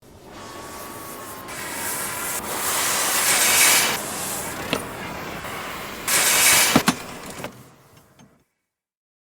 Metal Working
yt_pwdim-CGtZU_metal_working.mp3